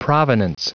Prononciation du mot provenance en anglais (fichier audio)
Prononciation du mot : provenance